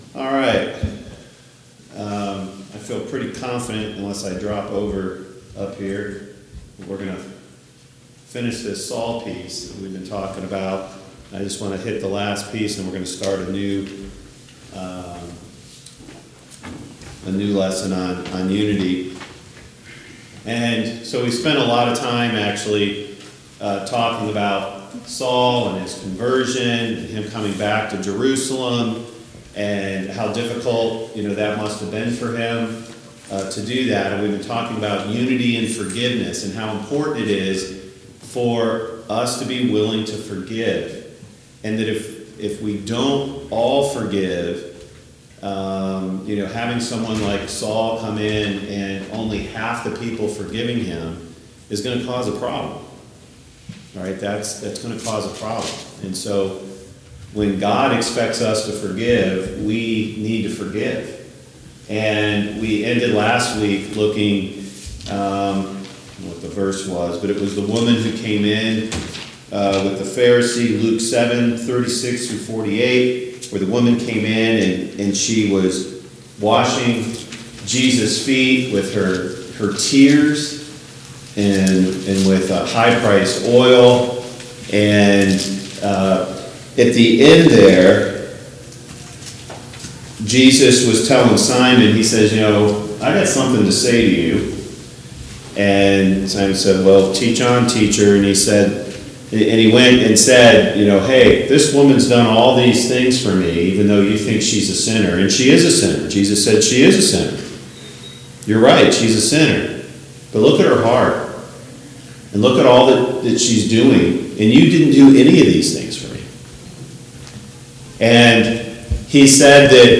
Service Type: Sunday Morning Bible Class Topics: Forgiveness